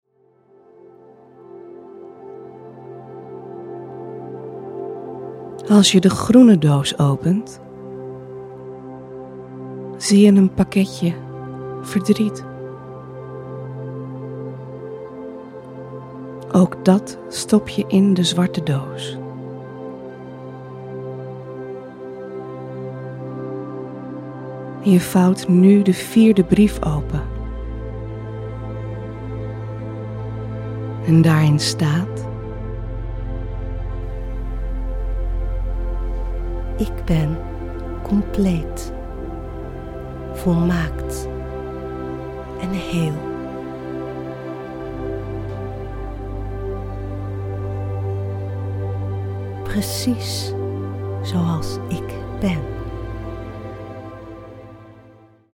Geleide visualisatie meditatie, waarin je een aantal programmeringen mag loslaten en opruimen.